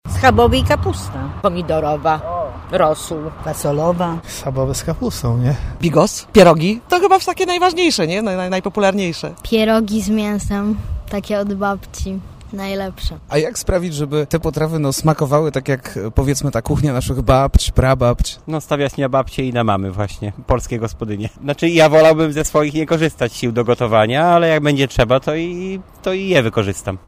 Zapytaliśmy warszawiaków o ulubione potrawy:
sonda-got.mp3